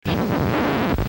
scratch5.mp3